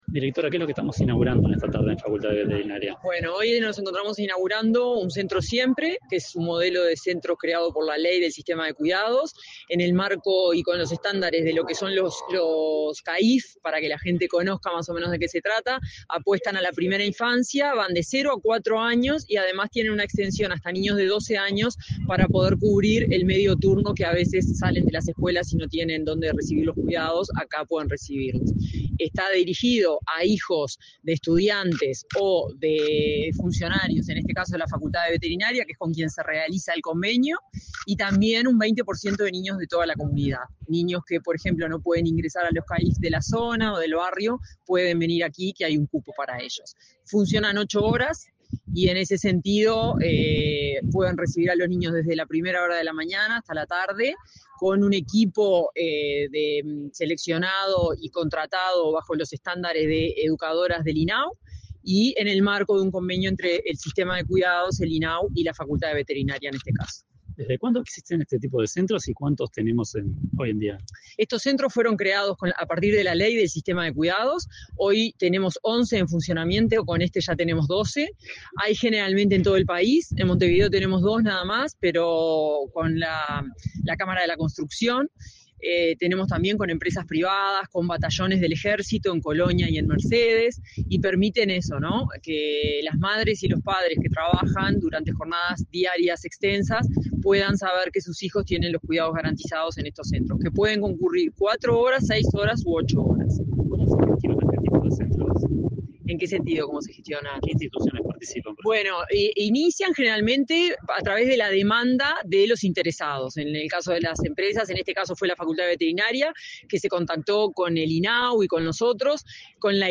Entrevista a la directora de Cuidados del Mides, Florencia Krall
Entrevista a la directora de Cuidados del Mides, Florencia Krall 20/08/2024 Compartir Facebook X Copiar enlace WhatsApp LinkedIn El Instituto del Niño y Adolescente del Uruguay (INAU) realizó, este 20 de agosto, el acto de inauguración del centro Siempre, creado en coordinación con la Facultad de Veterinaria. Tras el evento, la directora de Cuidados del Ministerio de Desarrollo Social (Mides), Florencia Krall, efectuó declaraciones a Comunicación Presidencial.